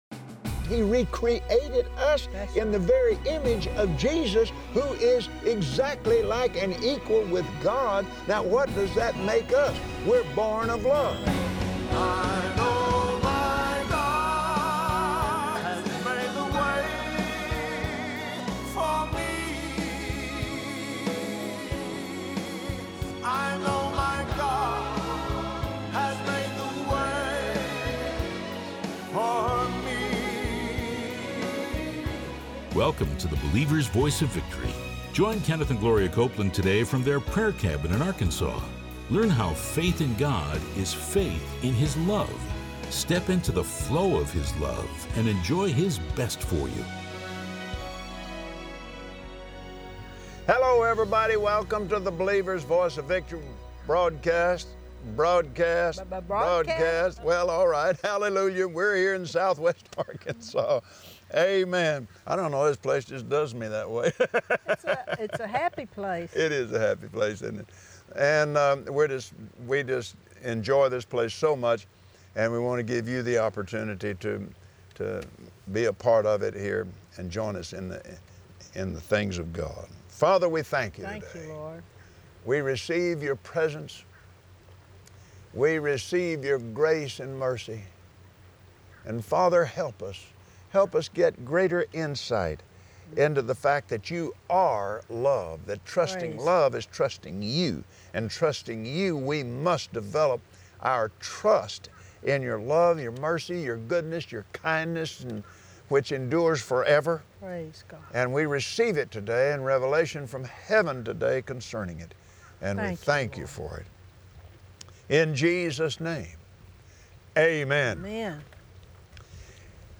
Believers Voice of Victory Audio Broadcast for Thursday 06/22/2017 If you walk in love, you’re fail proof! Watch Kenneth and Gloria Copeland on Believer’s Voice of Victory explain how faith in God is faith in Love that never fails.